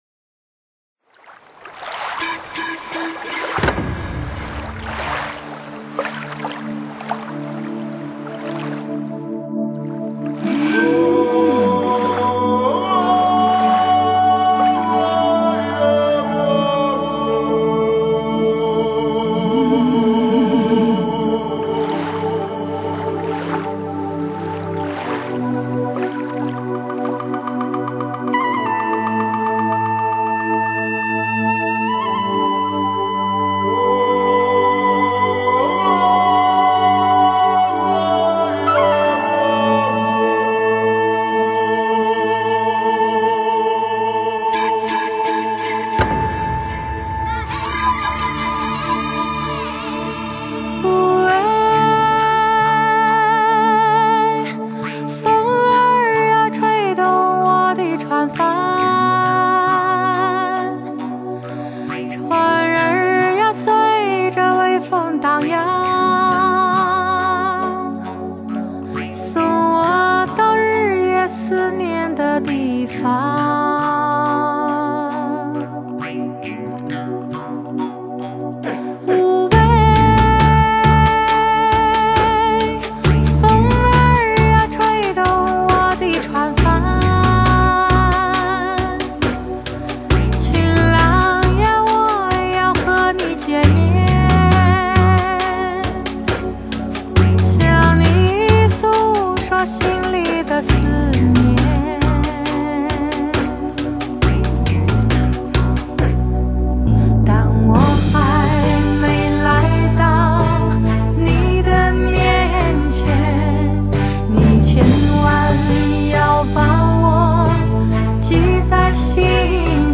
一付令人飘逸的嗓音